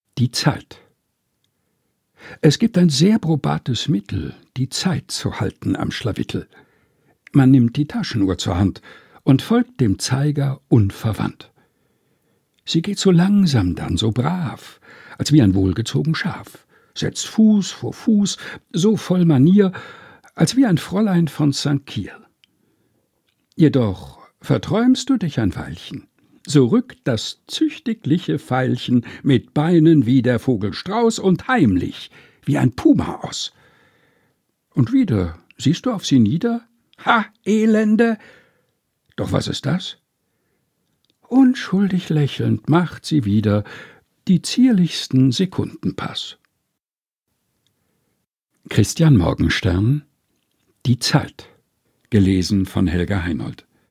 Texte zum Mutmachen und Nachdenken